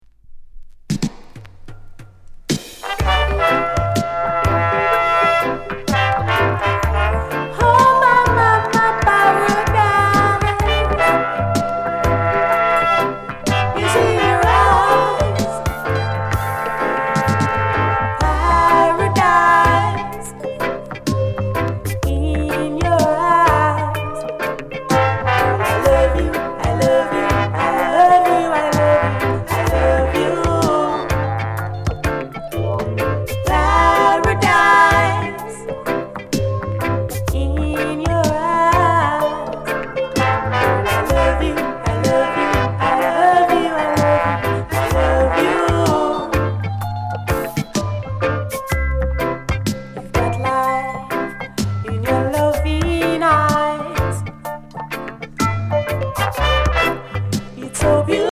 LOVERS ROCK